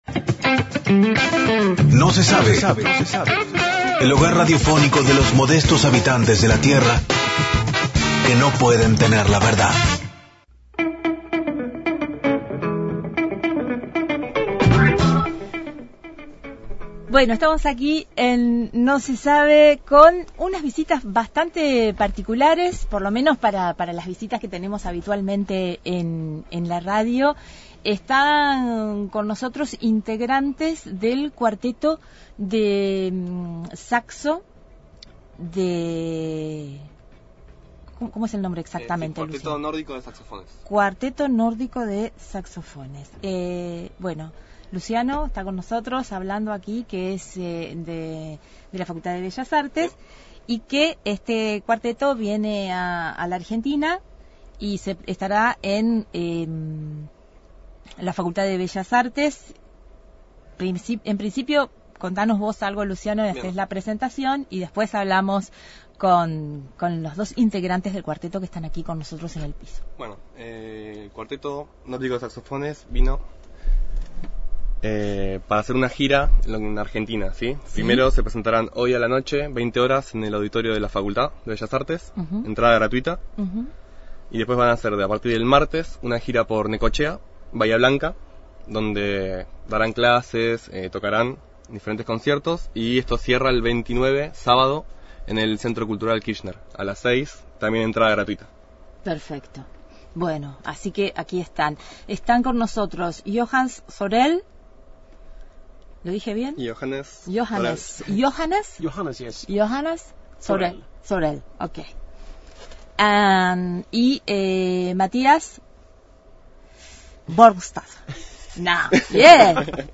Entrevista en el estudio de Radio Universidad a dos miembros del Cuarteto Nórdico que visita Argentina y se presentará en la Facultad de Bellas Artes. Programa: No Se Sabe.